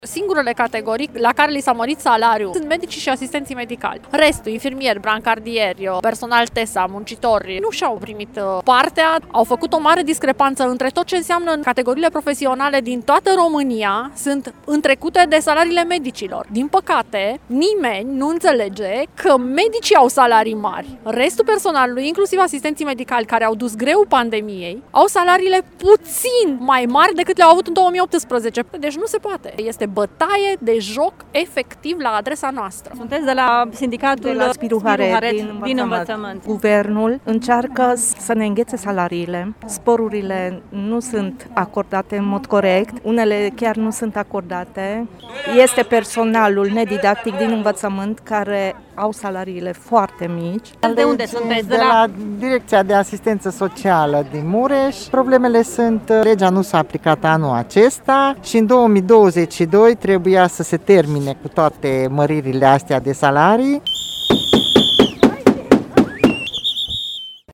Reprezentanții sindicatelor CNSLR Frăția, din Învățământ, din Sănătate, precum și din sectoarele private au pichetat azi Prefectura Mureș, nemulțumiți că, după ce a fost amânată aplicarea Legii Salarizării din cauza pandemiei, acum statul va îngheța salariile și sporurile.
Numeroase categorii de angajați au fost lăsați pe dinafară, astfel, un economist care lucrează în spital câștigă mai puțin decât o femeie de serviciu dintr-o instituție publică, spun protestatarii: